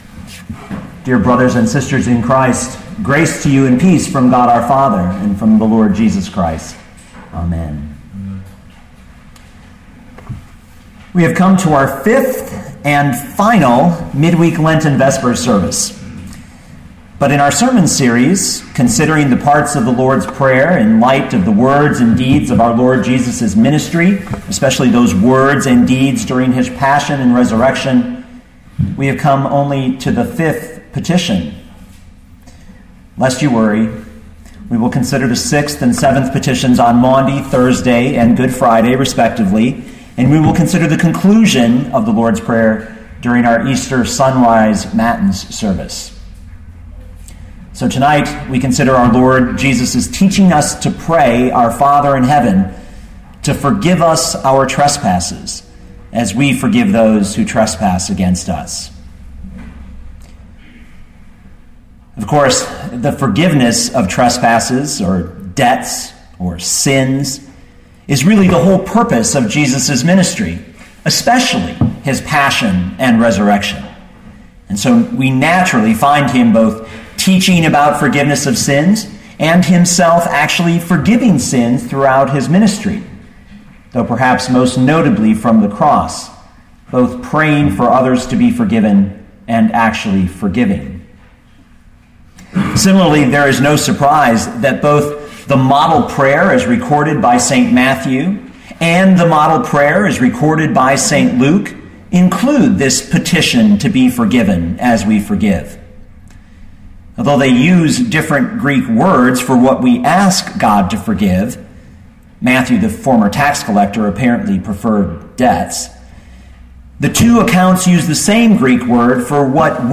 Luke 11:4 Listen to the sermon with the player below, or, download the audio.